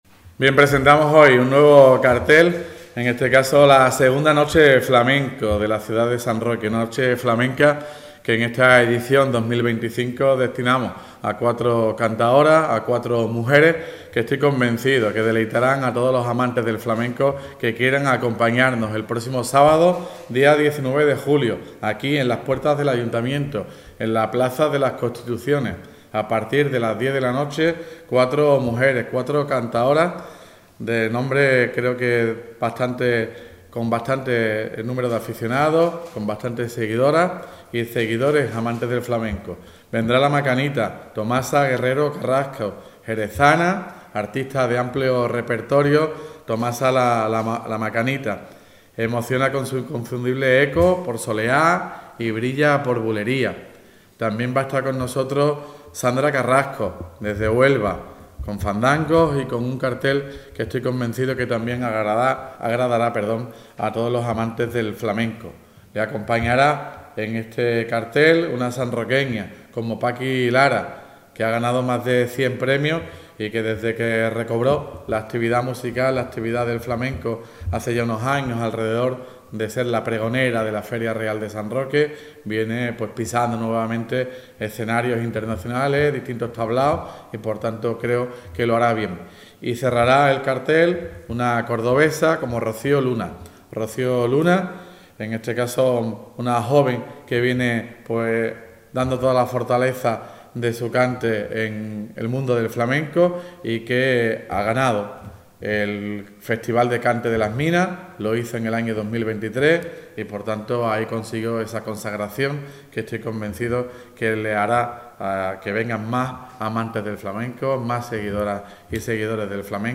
RUEDA DE PRENSA II NOCHE FLAMENCA 1 JULIO 2025.mp3